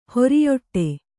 ♪ horiyoṭṭe